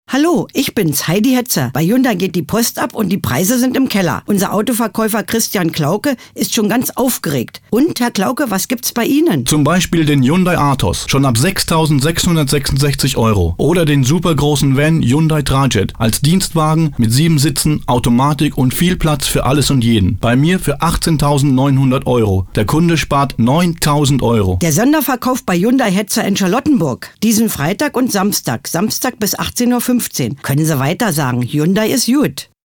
Radio: ALLE MAL HERHÖREN!